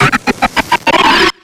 HIPPOPOTAS.ogg